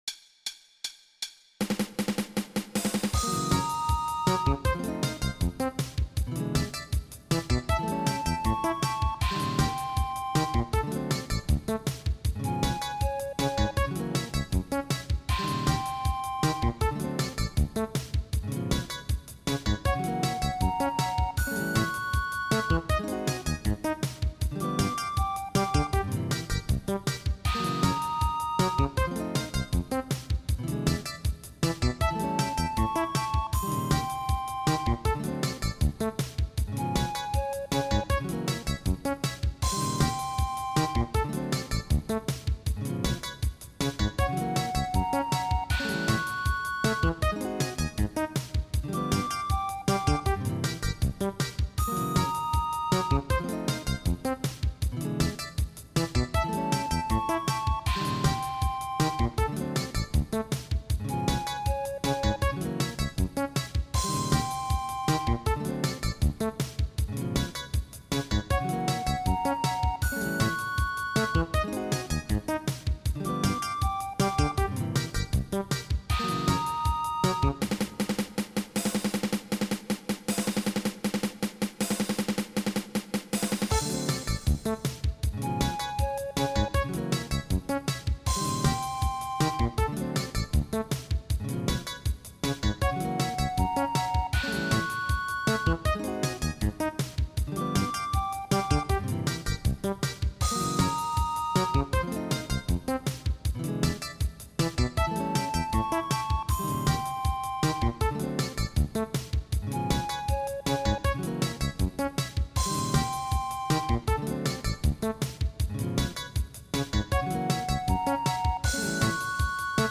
8beat